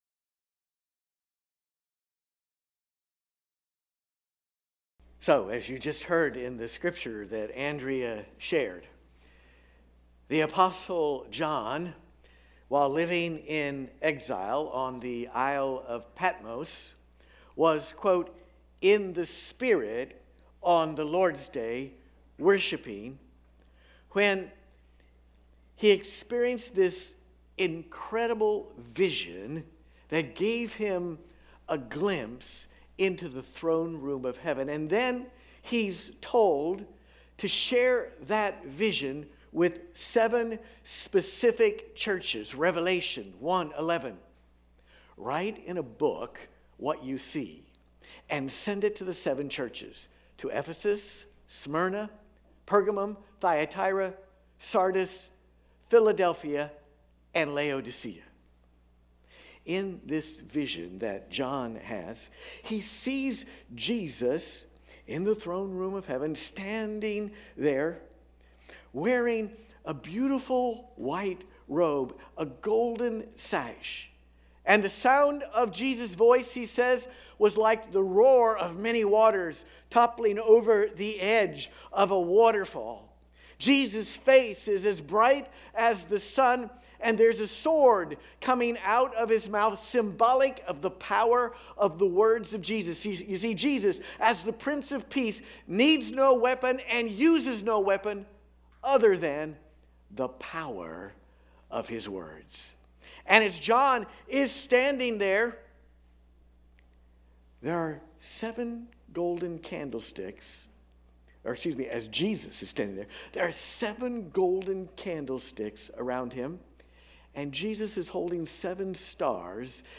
What is our unique vision and mission? This Sunday we’ll wrap up our “Called Together” sermon series by exploring those questions – because the truth is: God has given us an AMAZING and COMPELLING mission!